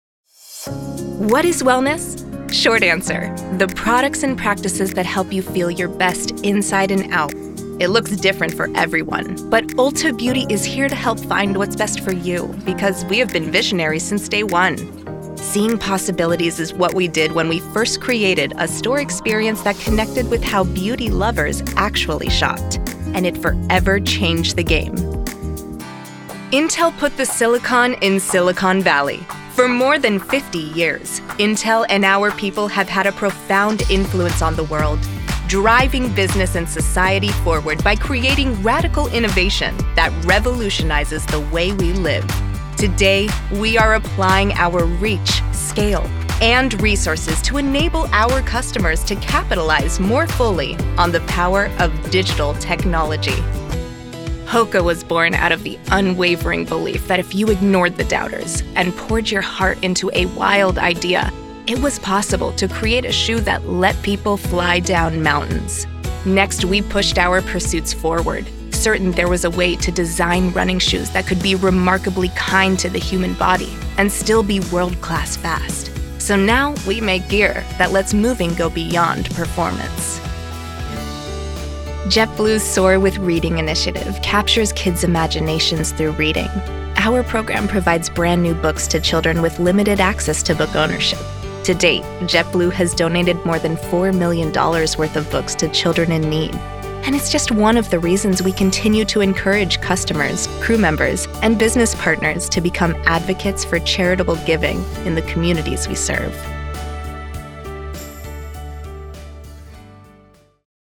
Corporate Showreel
Female
American Standard
Bright
Friendly
Warm